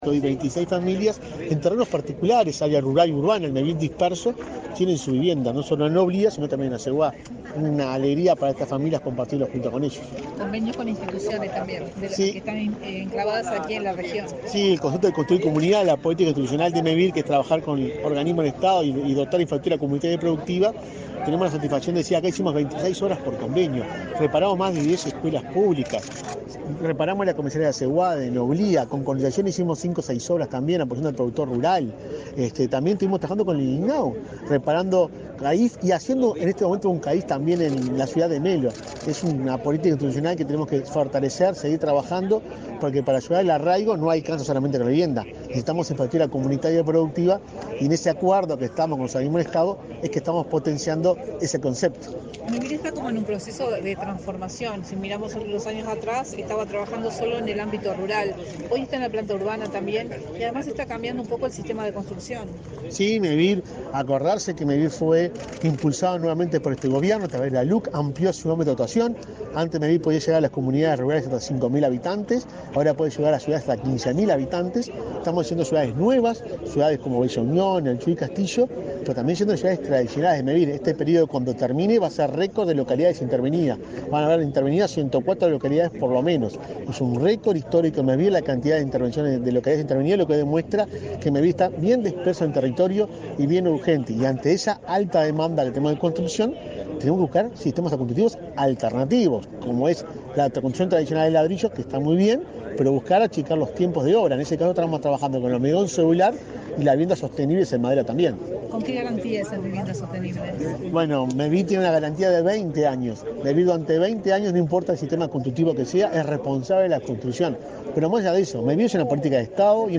Declaraciones a la prensa del presidente de Mevir, Juan Pablo Delgado
Declaraciones a la prensa del presidente de Mevir, Juan Pablo Delgado 27/04/2023 Compartir Facebook X Copiar enlace WhatsApp LinkedIn Este 27 de abril, Mevir inauguró 26 viviendas en las localidades de Noblía y Aceguá, en el departamento de Cerro Largo. En la oportunidad, el presidente de Mevir realizó declaraciones a la prensa.